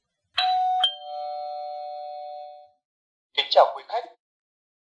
Âm thanh Chuông báo Kính Chào Quý Khách! (Giọng Nam)
Thể loại: Tiếng chuông, còi
Description: Âm thanh chuông báo “Kính Chào Quý Khách!” giọng nam chuẩn, file mp3 chất lượng cao, dùng làm nhạc chuông chào khách, thông báo tự động tại cửa hàng, siêu thị, khách sạn, bệnh viện, sân bay hay các không gian công cộng.
am-thanh-chuong-bao-kinh-chao-quy-khach-giong-nam-www_tiengdong_com.mp3